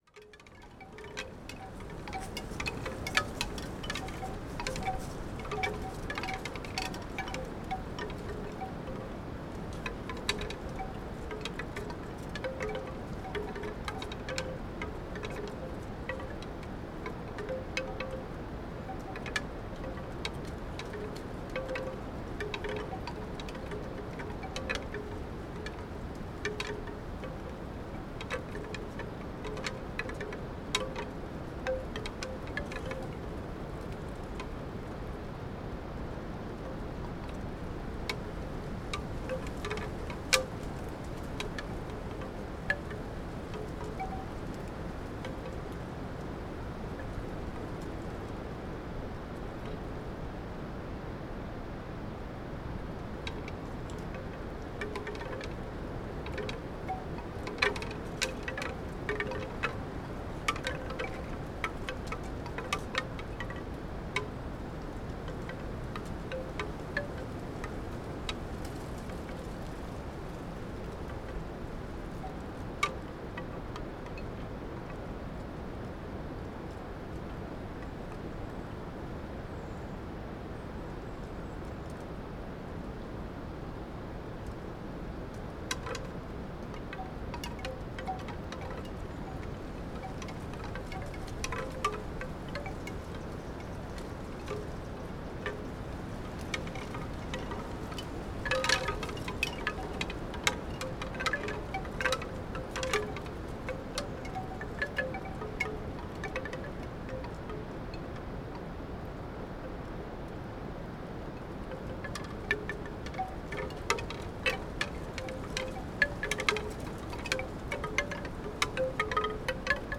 Wind Chimes, Teign Gorge near Castle Drogo - Bamboo - excerpt
autumn bamboo Castle-Drogo chimes Devon England field-recording Hunters-Path sound effect free sound royalty free Nature